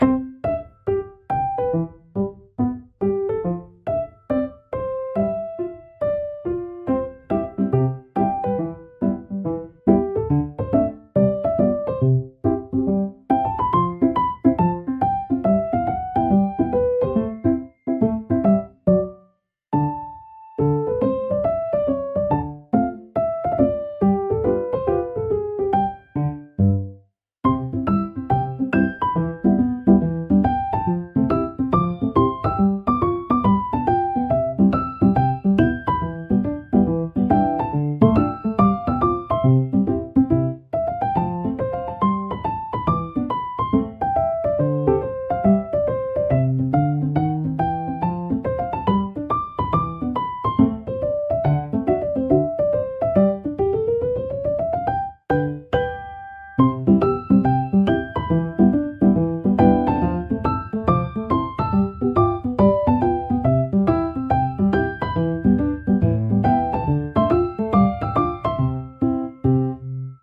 シンプルなメロディラインが心地よいリズムを生み、穏やかながらも前向きなムードを演出します。
ピアノの柔らかなタッチが集中をサポートし、疲れを癒す効果を発揮します。